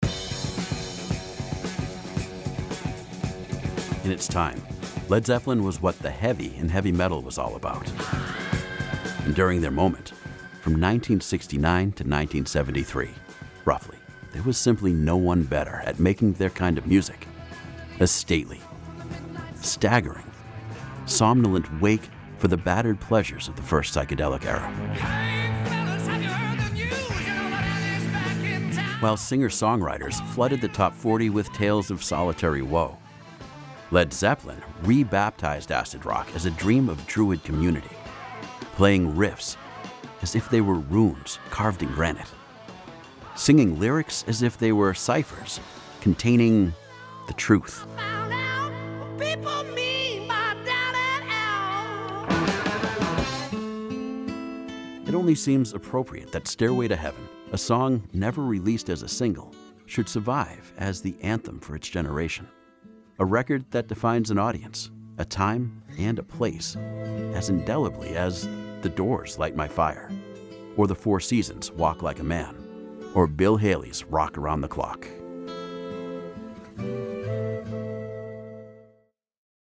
Mature Adult, Adult
The friendly, accessible, trustworthy, authoritative, “coffee-laced-with-caramel” voice of the nerdy, fun dad next door.
standard us
e-learning